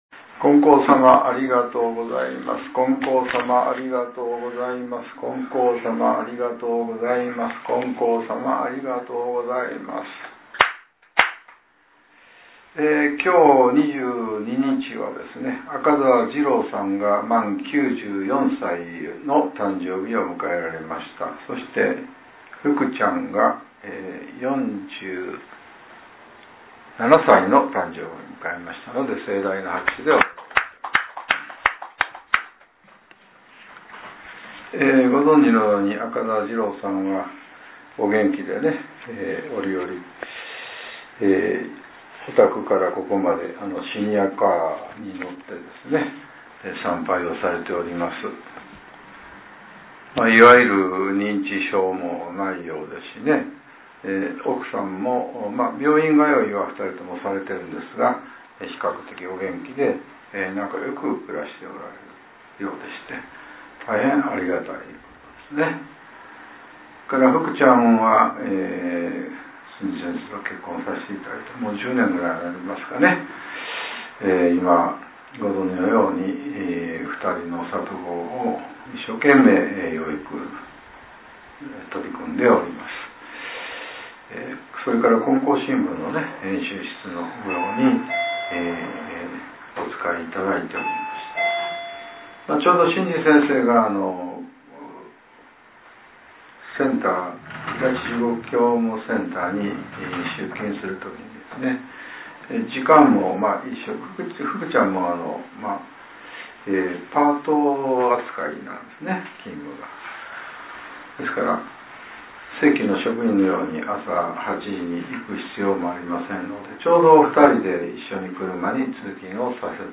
令和６年１１月２２日（朝）のお話が、音声ブログとして更新されています。